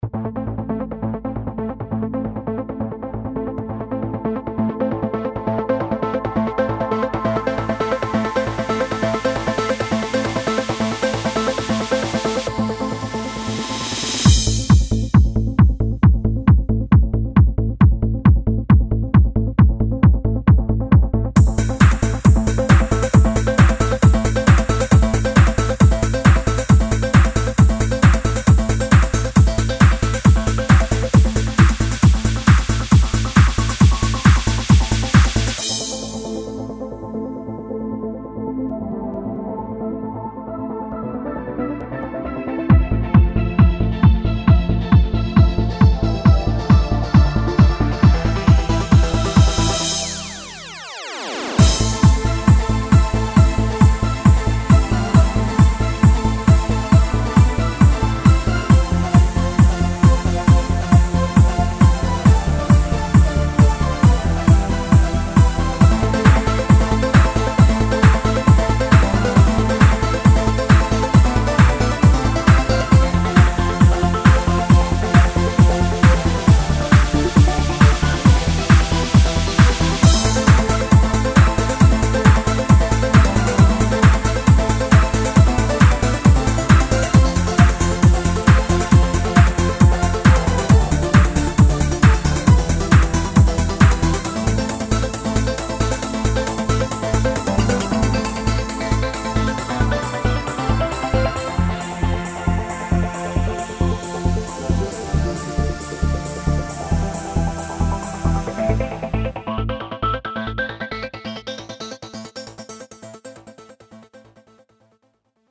Multisample-based, fully-featured synthesizer engine
Trance